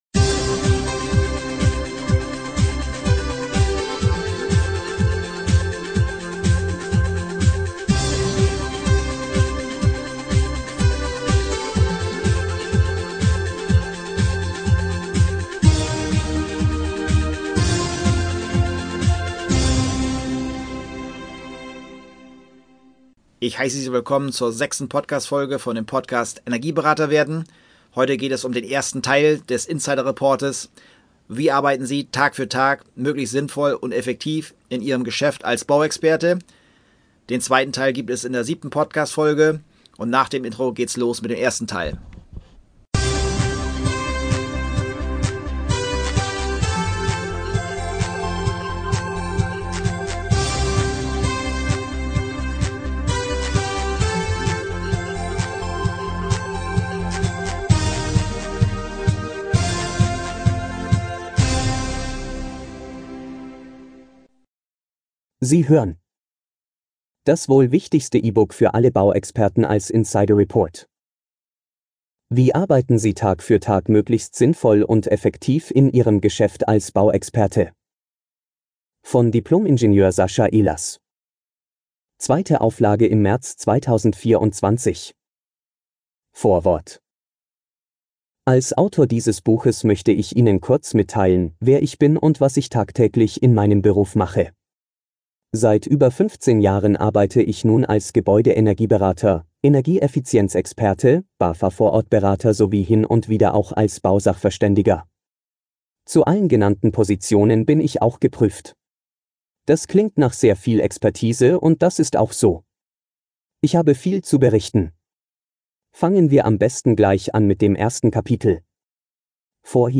Energieberater werden | In 90 Tagen ausgebucht... - Hörbuch Teil 1.